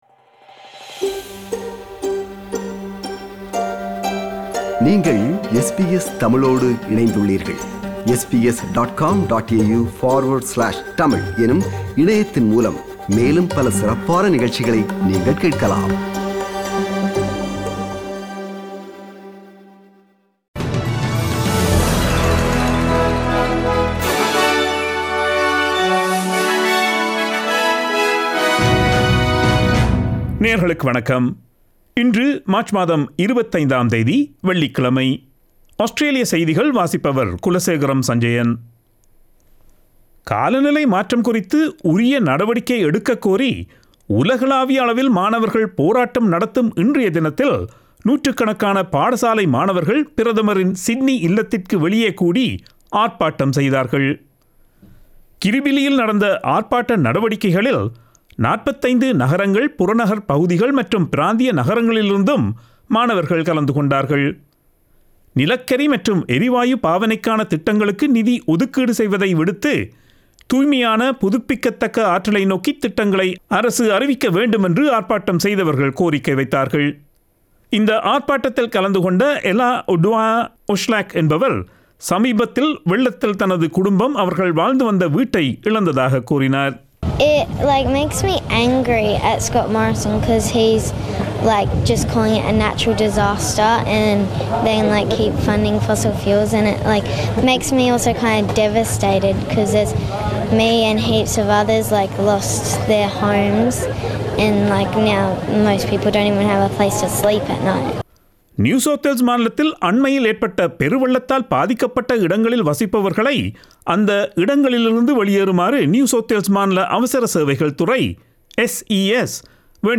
Australian news bulletin for Friday 25 March 2022.